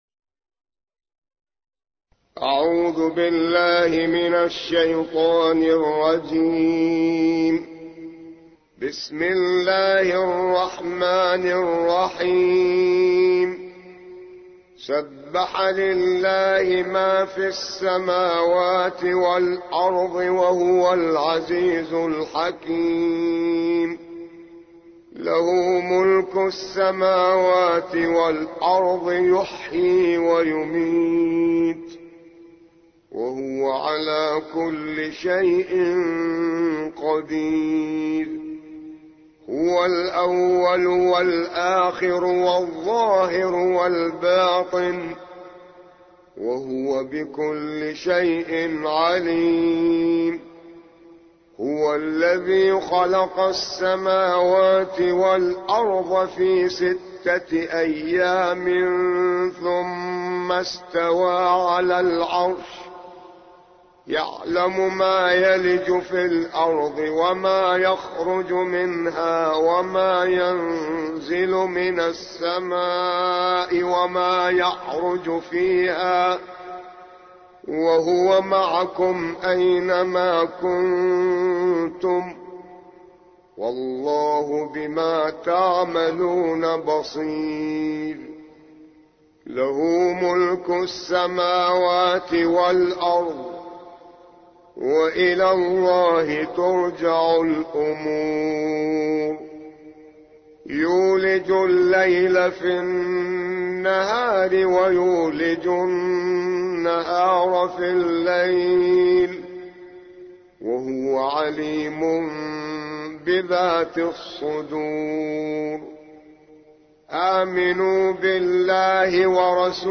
57. سورة الحديد / القارئ